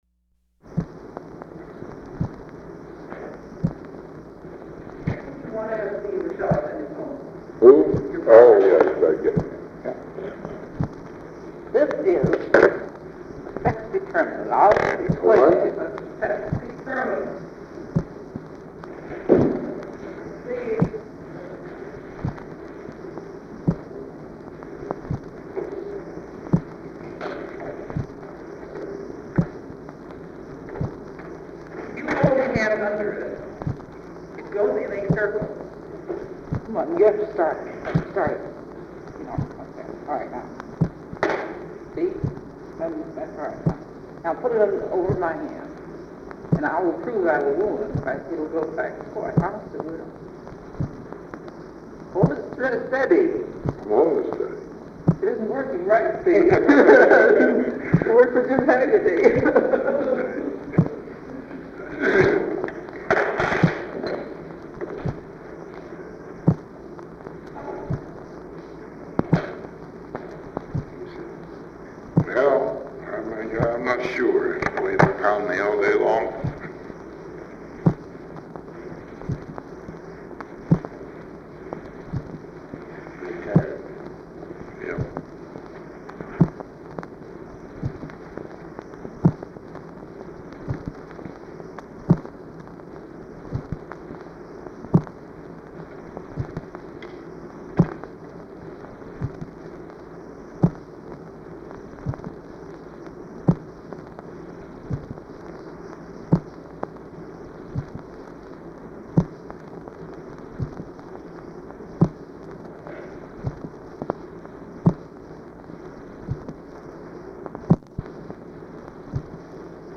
The conversation is very brief and largely unintelligible. Two staff members are present in the Oval Office.
Following their exchange, Eisenhower addresses several remarks to an unidentified man.